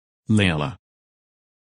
Написание и аудио произношение – Spelling and Audio Pronunciation